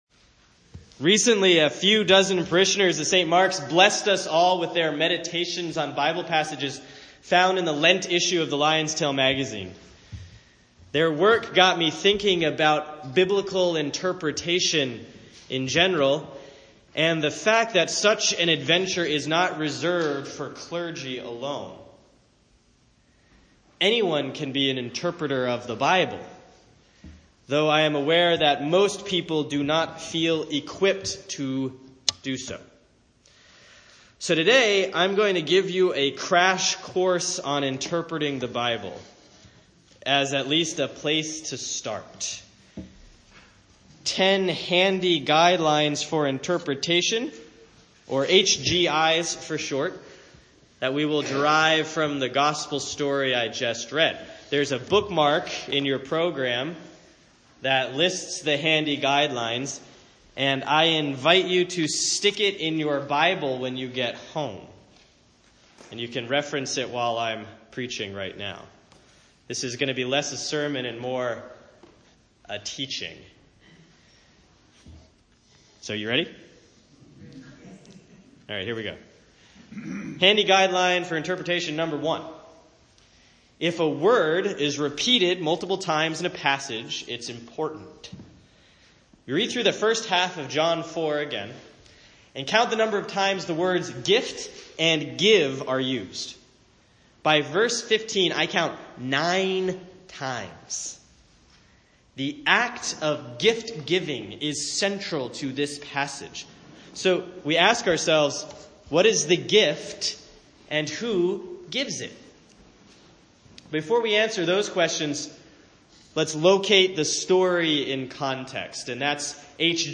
10 Handy Guidelines for interpreting the Bible accompany this sermon about Jesus' encounter with the woman at the well.